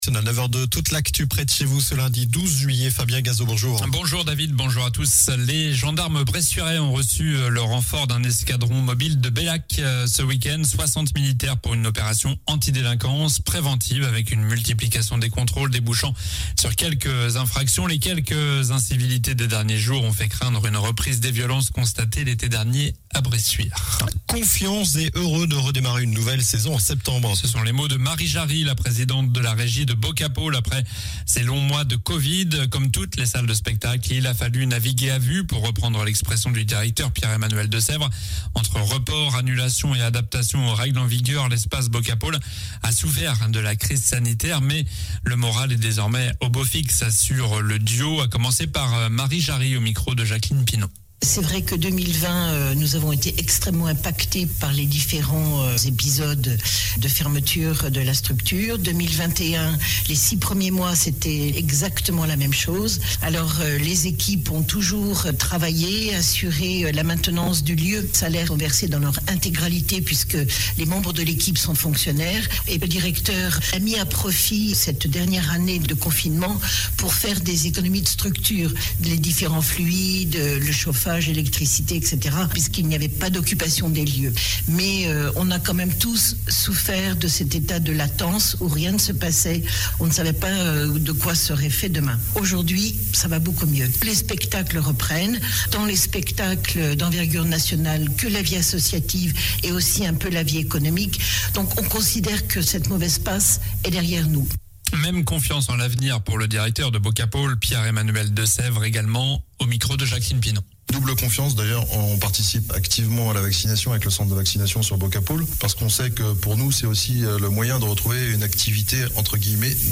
Journal du lundi 12 juillet (midi)